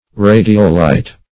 radiolite - definition of radiolite - synonyms, pronunciation, spelling from Free Dictionary